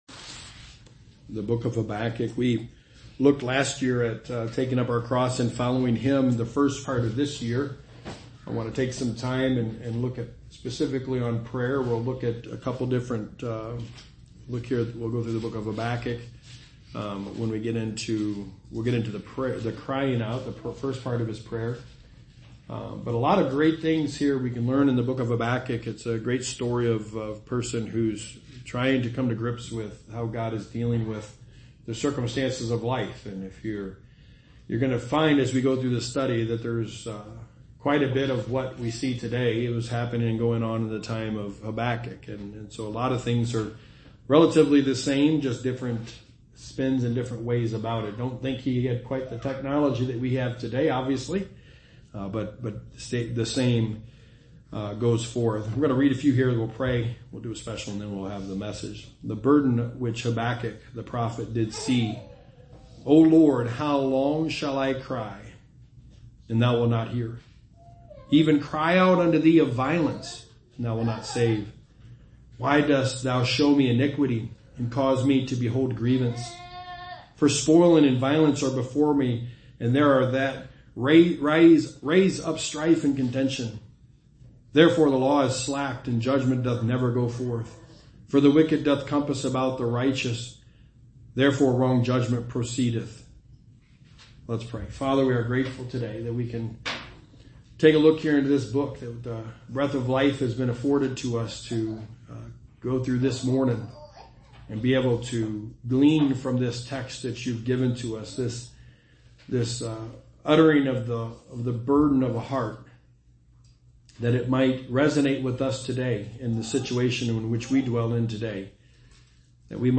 The Book of Habakkuk – Sermon #1
Passage: Habakkuk 1:1-4 Service Type: Sunday Morning